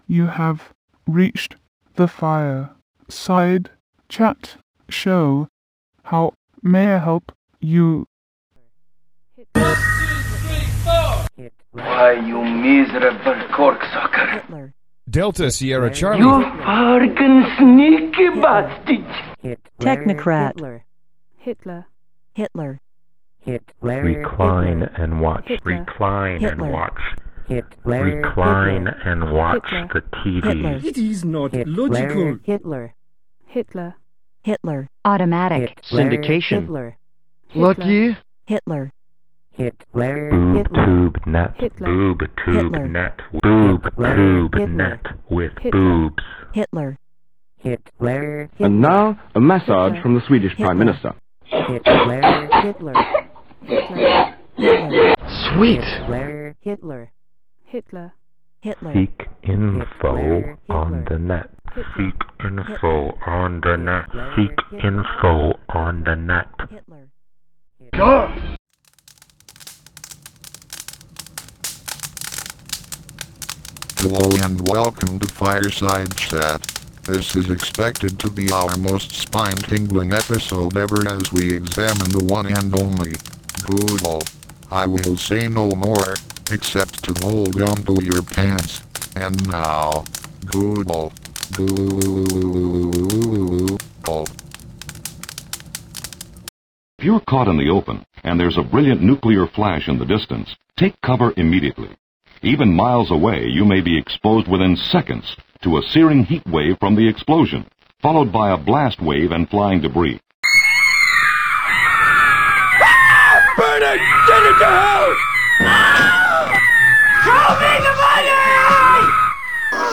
FSCS is generally intended to provide excruciatingly boring technical information regarding software and popular websites on the internet in an enlightened fashion with our crack team of text-to-speech (aka TTS/t2s) professionals.
The only true automated pawdcast. 7:31